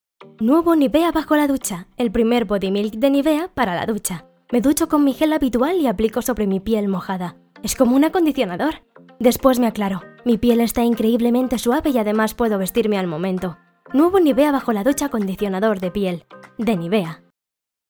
Voz jóven, cercana y natural.
kastilisch
Sprechprobe: Werbung (Muttersprache):
Young, close and natural voice.